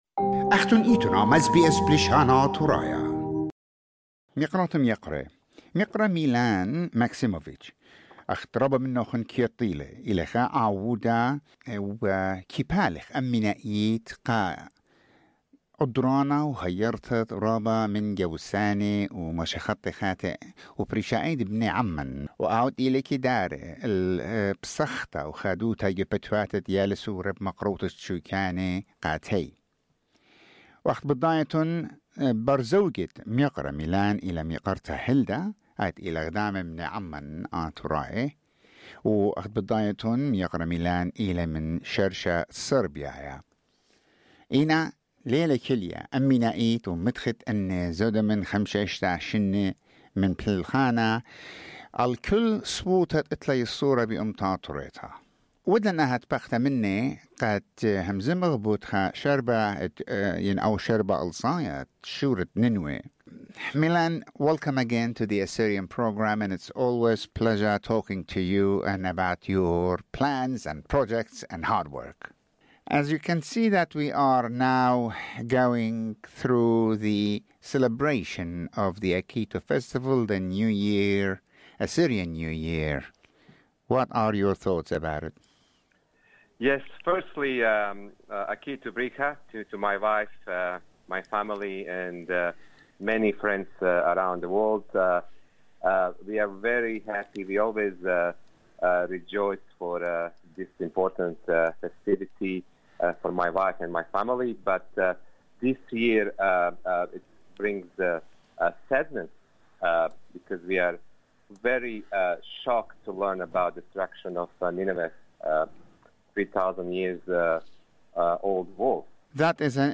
The interview is in English with Assyrian summary at the end.